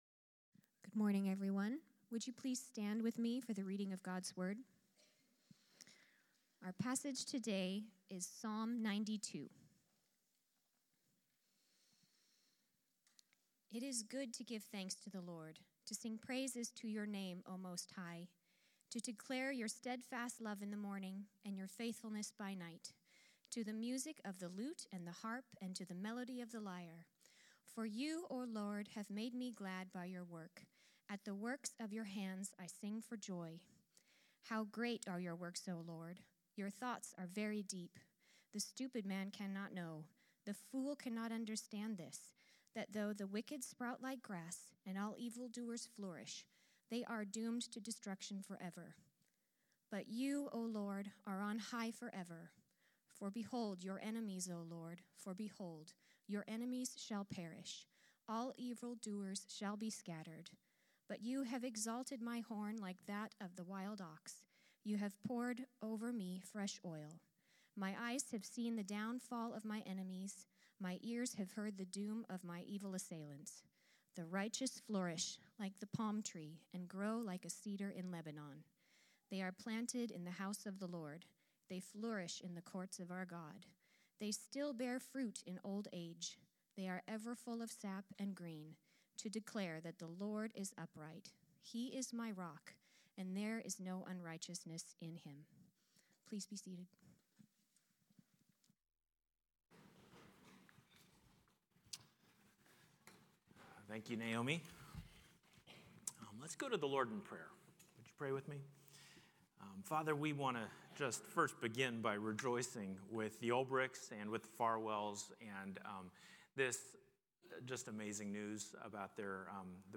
Sermons | Grace Church - Pasco